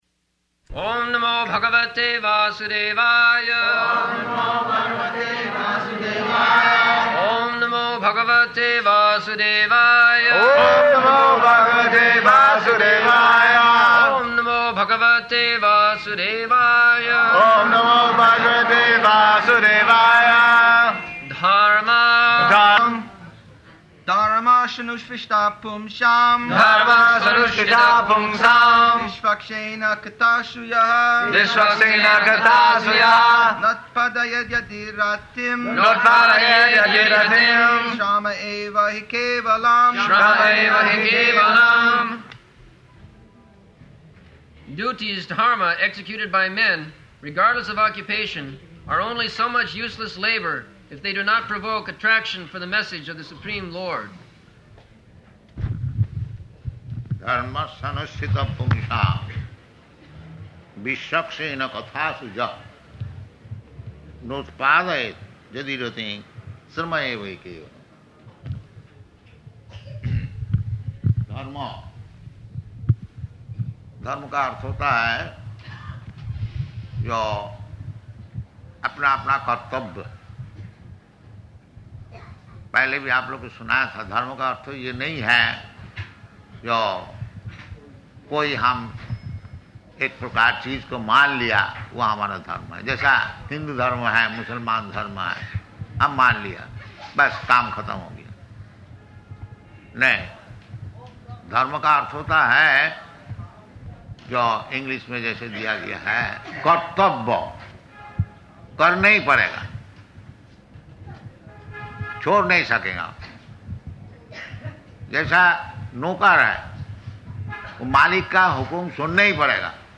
March 23rd 1975 Location: Calcutta Audio file
[devotees repeat] [leads chanting of verse, etc.]